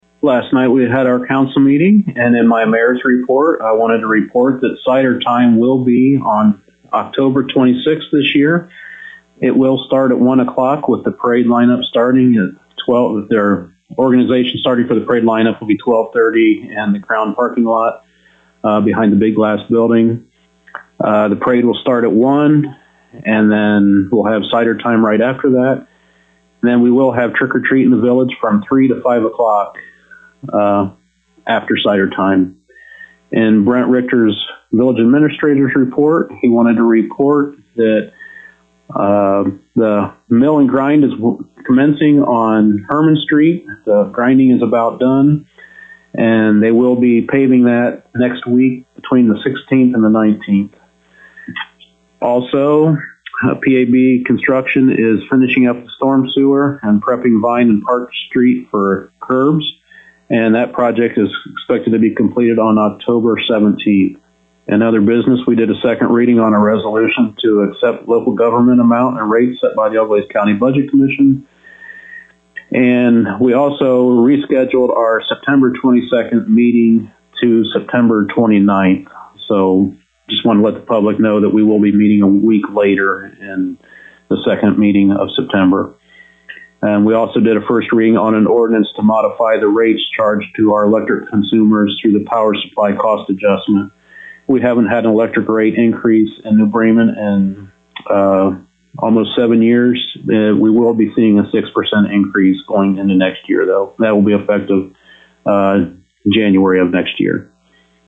To hear the summary with New Bremen Mayor Bob Parker: